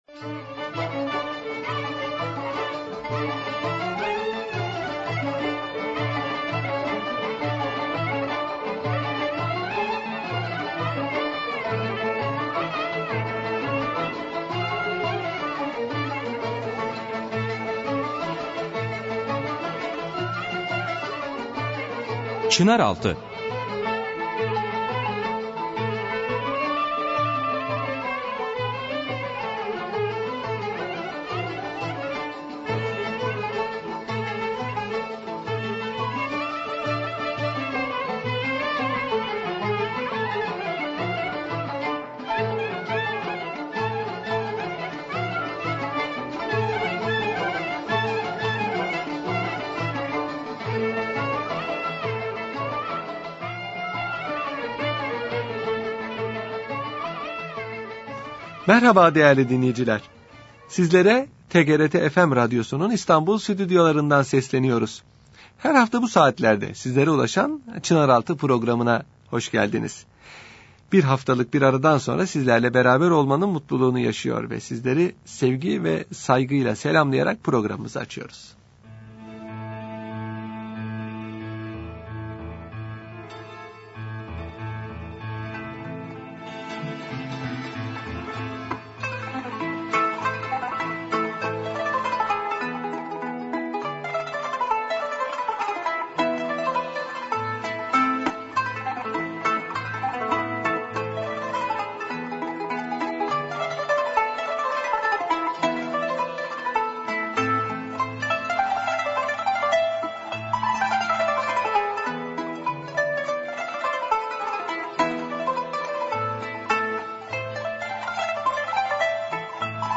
Radyo Programi - Sarayda teknık egıtım